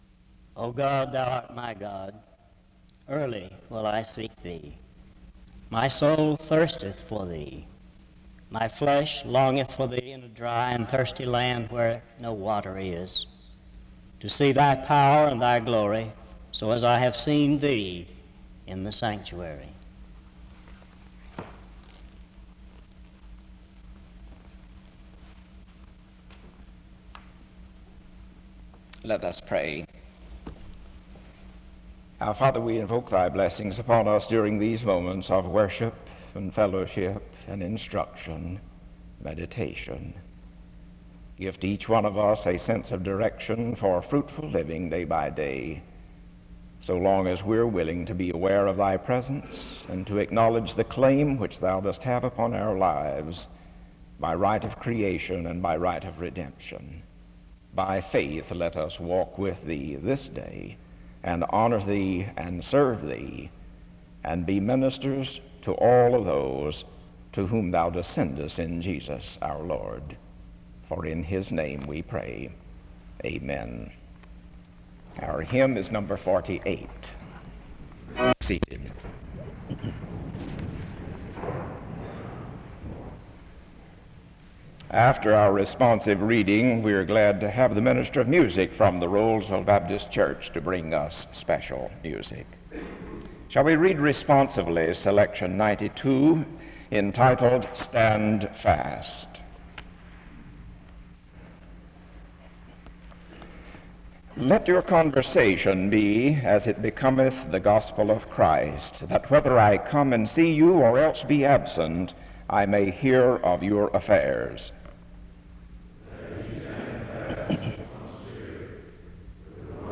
The service starts with a scripture reading from 0:00-0:22. A prayer is offered from 0:28-1:14. A responsive reading is led from 1:24-3:23.
SEBTS Chapel and Special Event Recordings SEBTS Chapel and Special Event Recordings